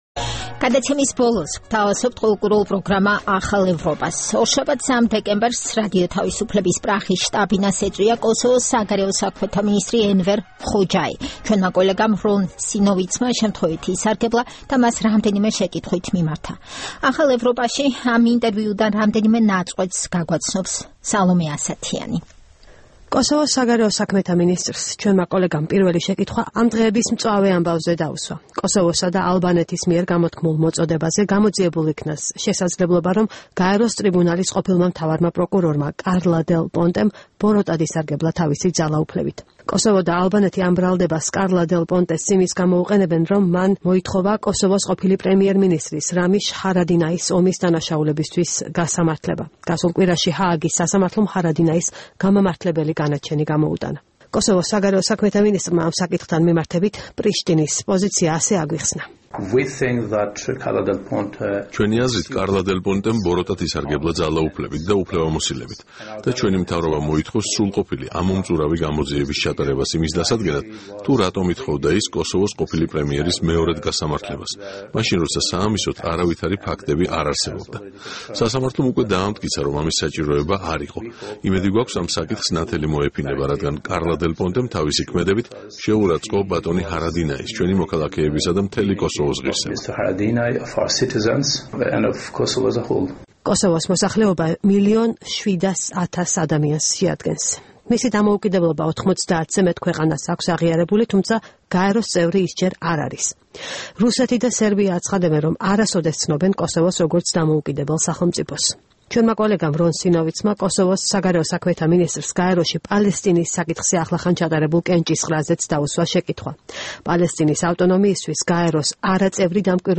ინტერვიუ კოსოვოს საგარეო საქმეთა მინისტრთან